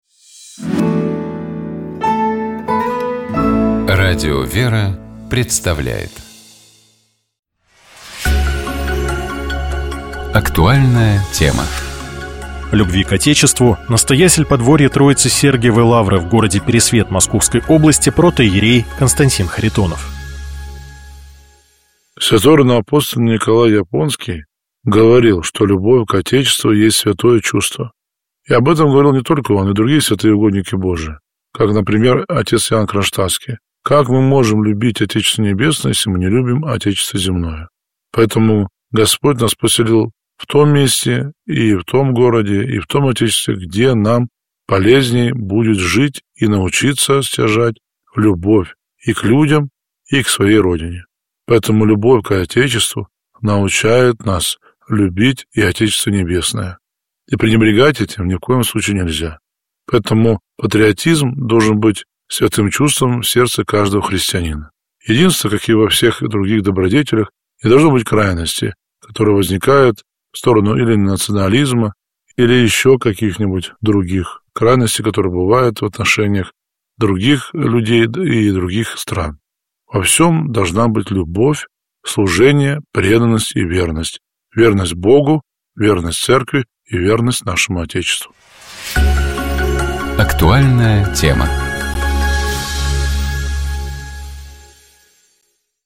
Псалом 26. Богослужебные чтения Скачать 16.02.2026 Поделиться Помазание на царство — древнейший чин наделения человека властью.